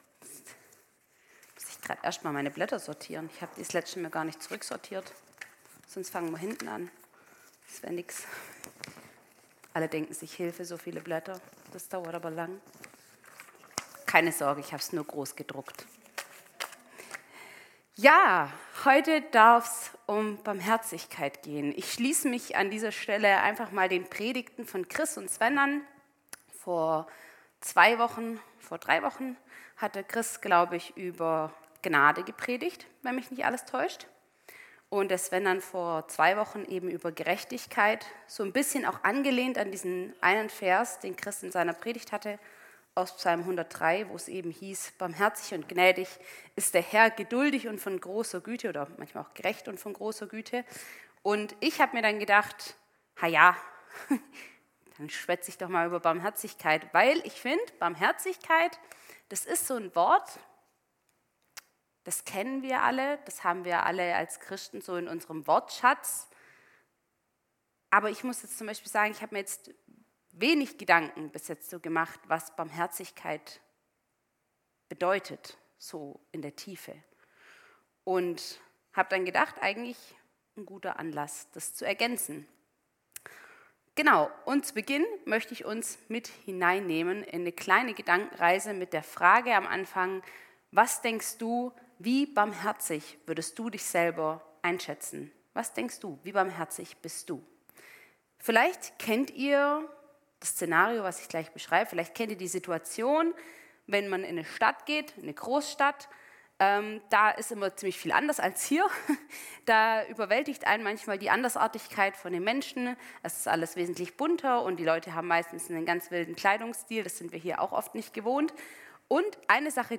Gottesdienst am 30.06.2024